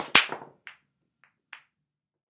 На этой странице собраны звуки бильярда: от четких ударов кием до глухого стука шаров и их падения в лузу.
Тихий звук удара шара о шар в бильярде